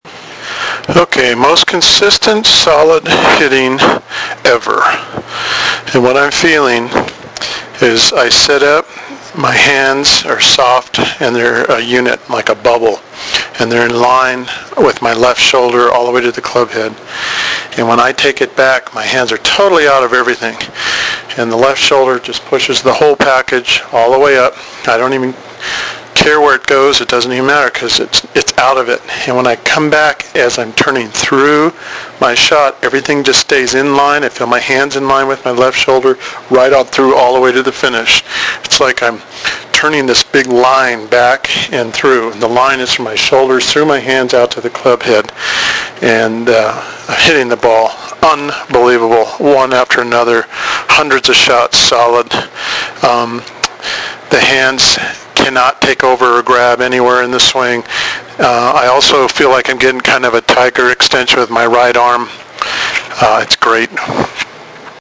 This was essentially taking audio notes as my method progressed.
MOST SOLID CONSISTENT HITTING EVER
** Oh, … This was in my living room and after a couple of months, I put a hole through the middle of the net from hitting the same spot over and over…  The Titleist  Pro V1 is still in the wall of that house.